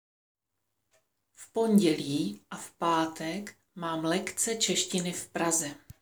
Tady si můžete stáhnout audio na výslovnost asimilace: V pondělí a v pátek.